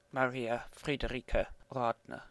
Maria Friderike Radner (German: [ˈʁaːdnɐ]